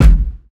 SouthSide Kick Edited (66).wav